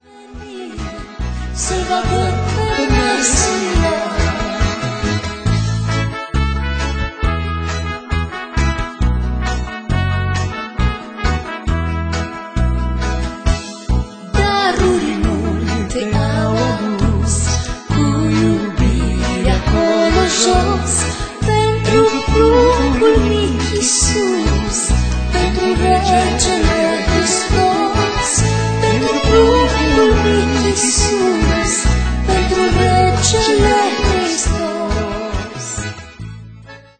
se datoreaza nu numai aranjamentului orchestral deosebit